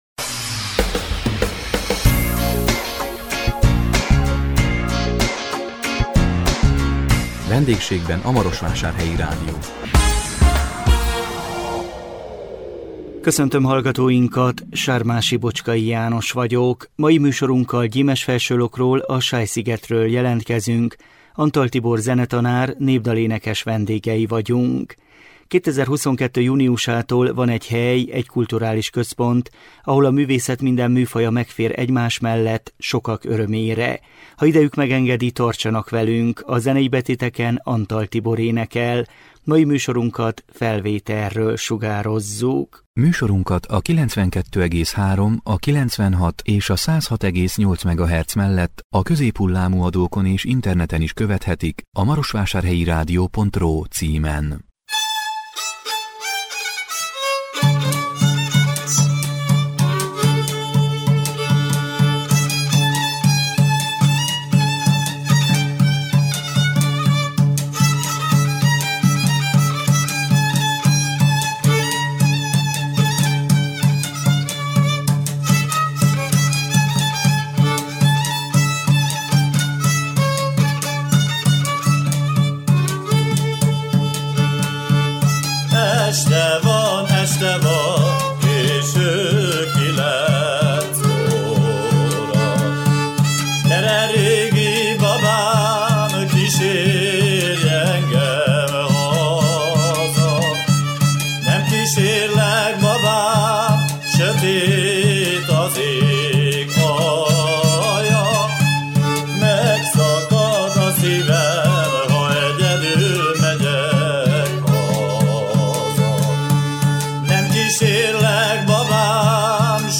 Gyimesfelsőlokról, a Sáj szigetről jelentkeztünk